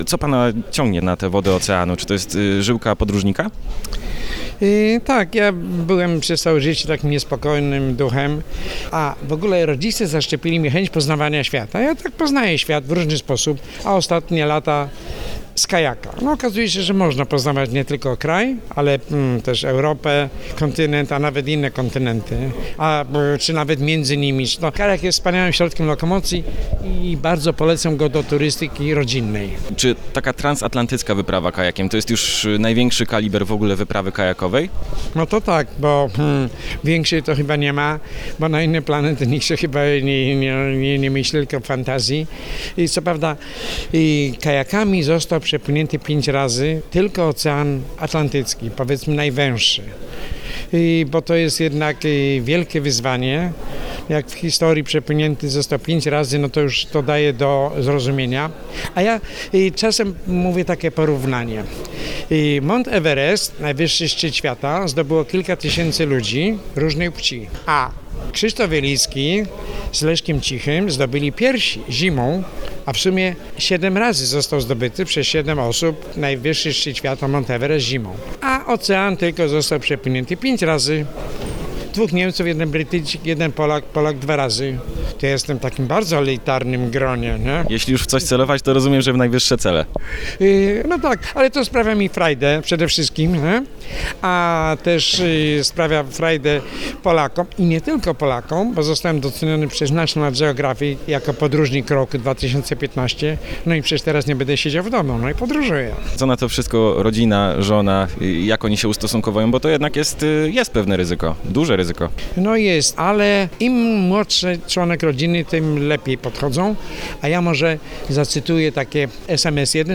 W Radiu Stargard Aleksander Doba opowiedział o tym, dlaczego akurat kajak, co myśli rodzina, kiedy słyszy o kolejnej wielkiej i samotnej wyprawie i jak to się dzieje, że jego kajak jest niezatapialny.
Doba wywiad water arena obrobiony_calosc.mp3